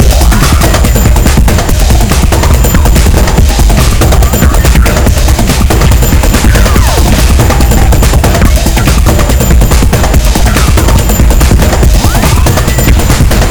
Added sound demo of all 10 instances playing for fun.